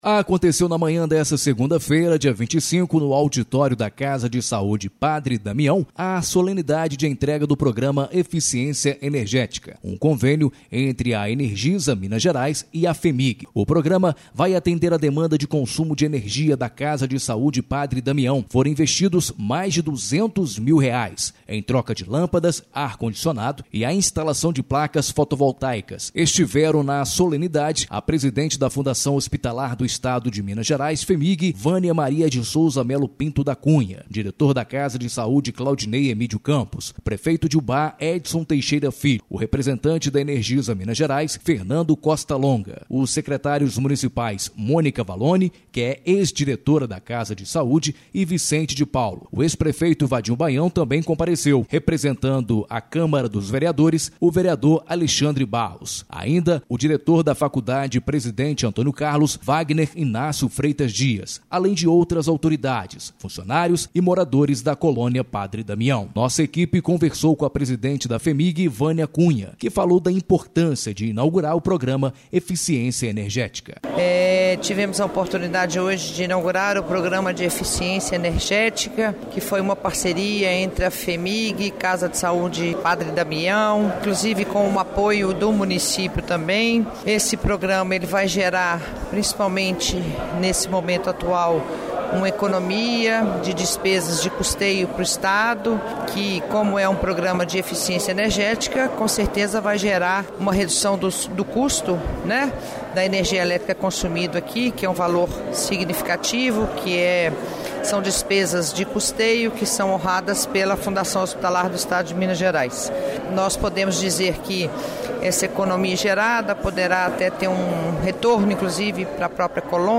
Aconteceu na manhã de segunda – feira (25), no auditório da Casa de Saúde Padre Damião – a solenidade de entrega do Programa Eficiência Energética – um convênio entre a Energisa Minas Gerais e a FHEMIG.
Nossa equipe conversou com a Presidente da FHEMIG – Vânia Cunha – que falou da importância de inaugurar o Programa Eficiência Energética.